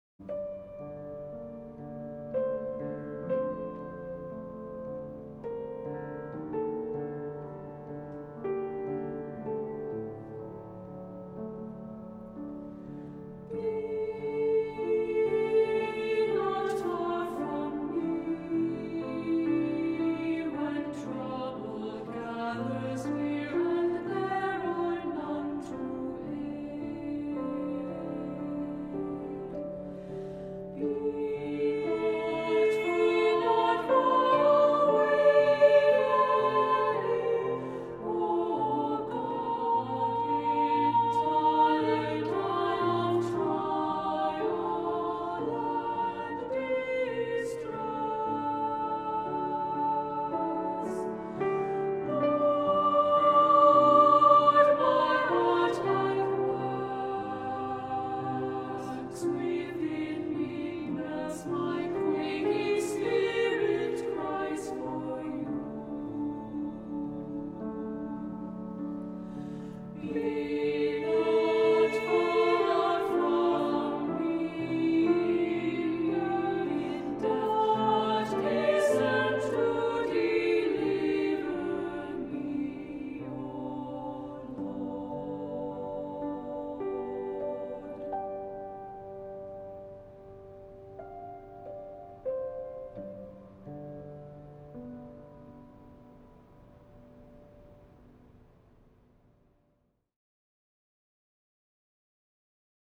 Voicing: Two-part mixed; Two-part equal